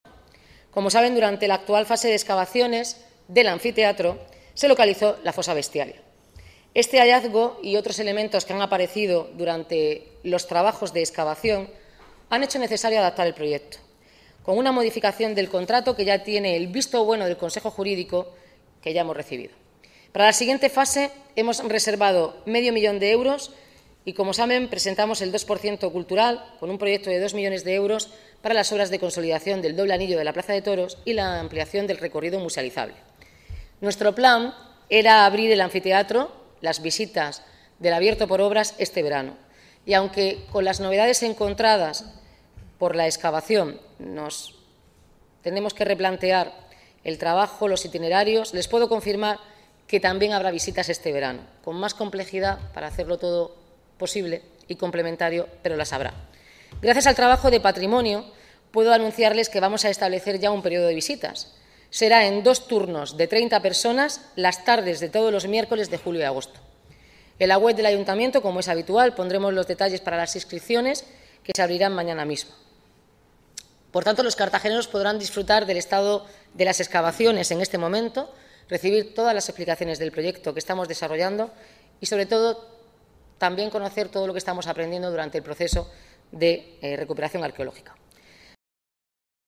La alcaldesa Noelia Arroyo avanza en el pleno extraordinario del Estado del Municipio la reserva de medio millón de euros para continuar las excavaciones